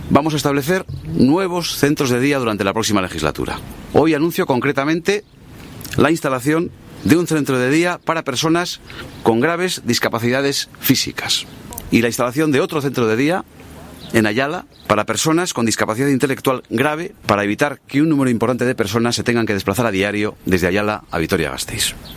• Ramiro González en el centro sociocultural de mayores de Ariznabarra 11/05/2015